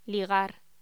Locución: Ligar
voz